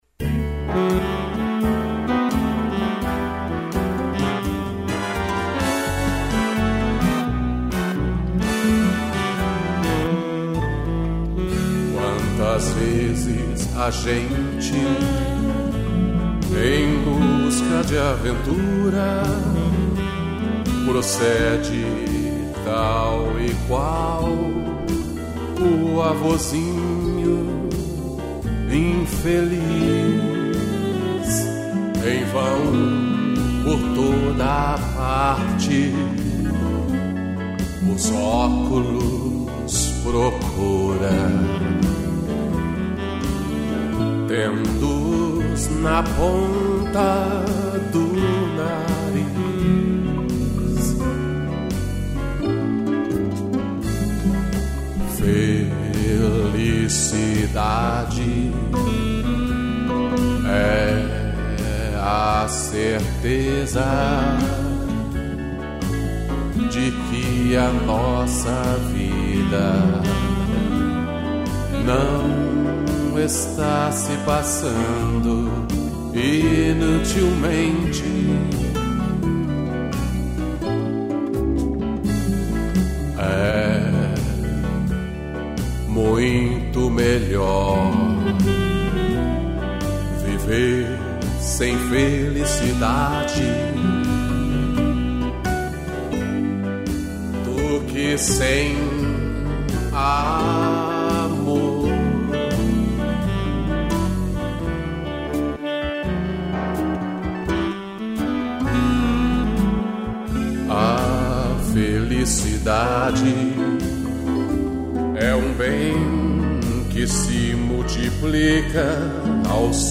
violão e sax